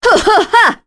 Naila-Vox_Attack2.wav